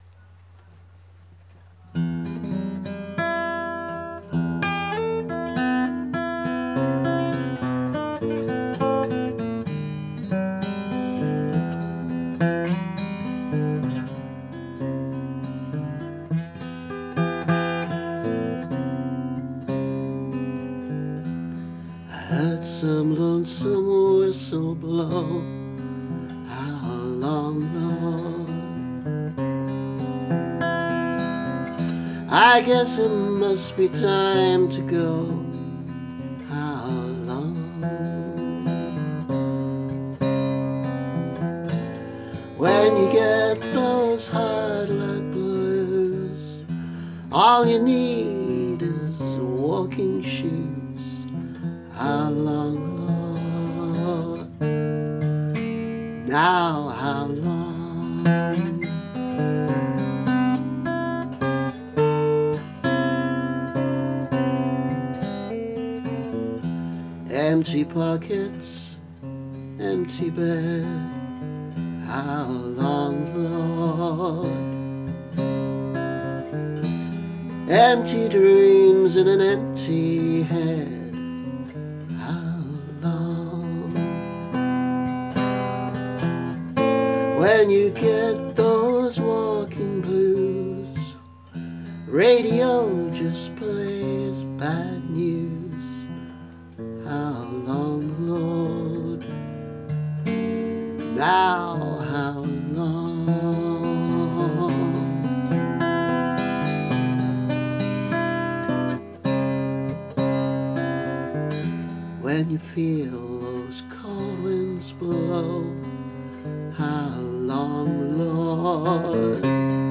A very young song with an obvious blues influence, though not a conventional blues structure.
Alternative version from video – audio was better than the video!